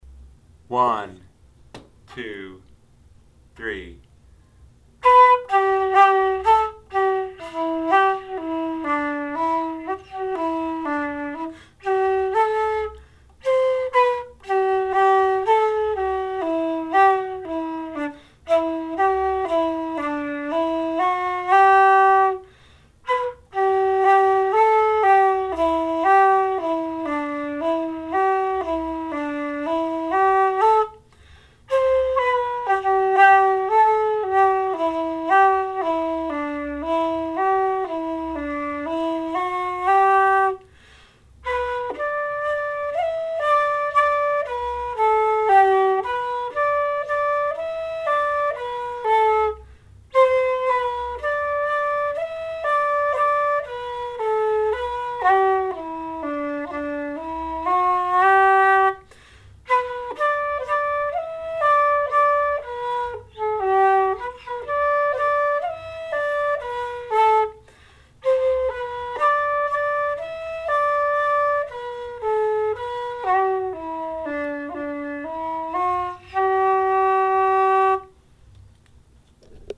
SLIP JIGS - Hardiman the Fiddler & Na Ceannabhan Bhana
Na-Ceannabhan-Bhana-slow.mp3